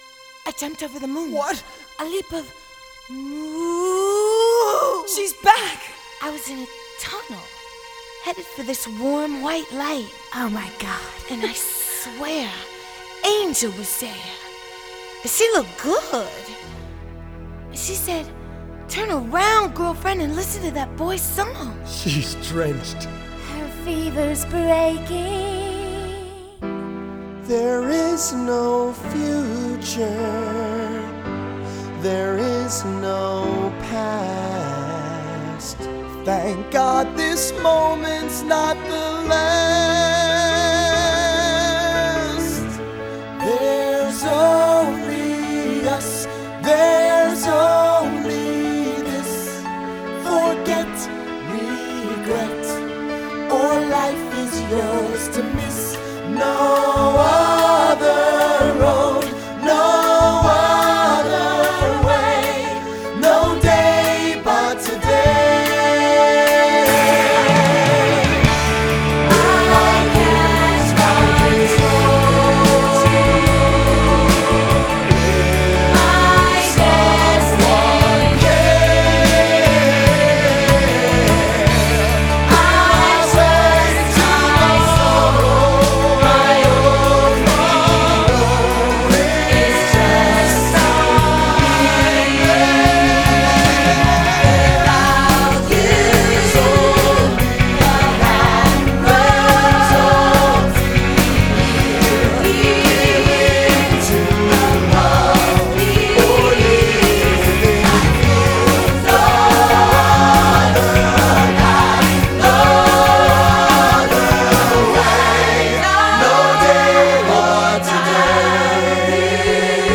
[Cast Recording]